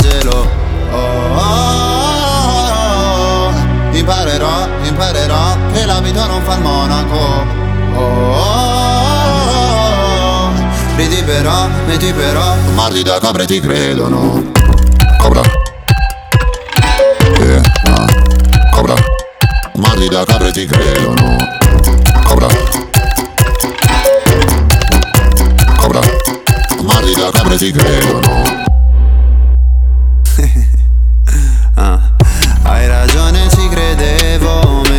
Скачать припев
2021-06-10 Жанр: Поп музыка Длительность